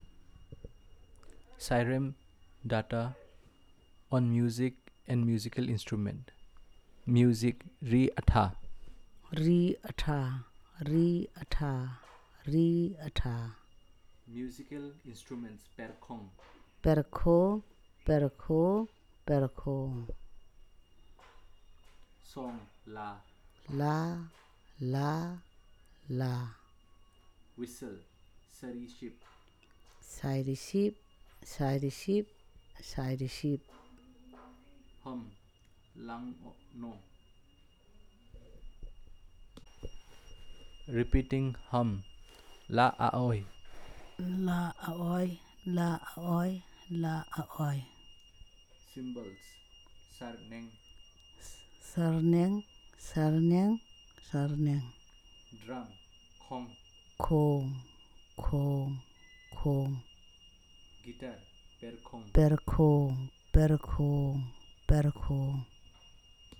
NotesThis is an elicitation of words about music and musical instruments using the SPPEL Language Documentation Handbook.